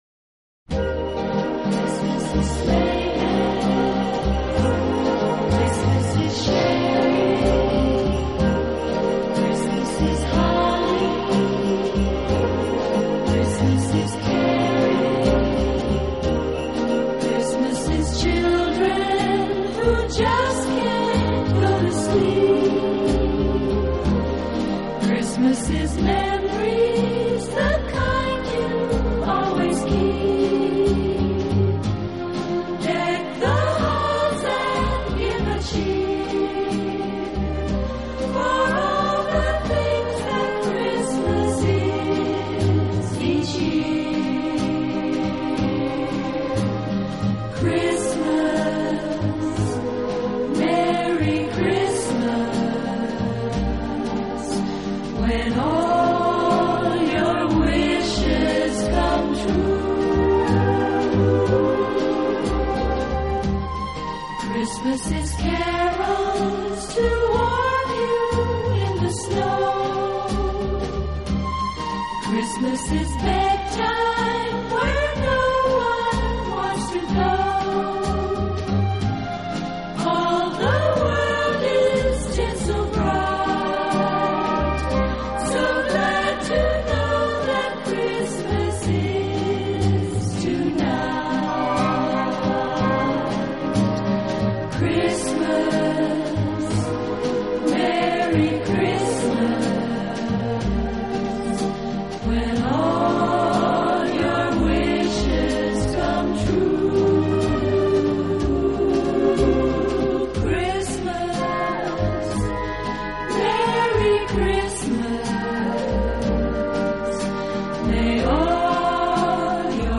【轻音乐专辑】
音乐风格：Christmas